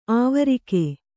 ♪ āvarike